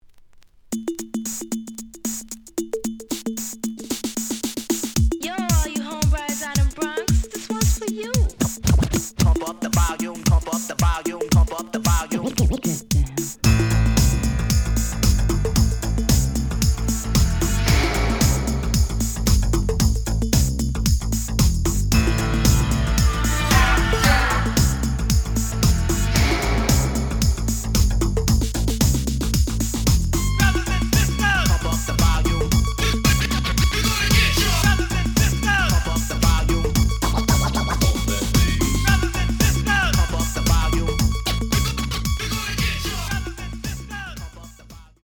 The audio sample is recorded from the actual item.
●Format: 7 inch
●Genre: House / Techno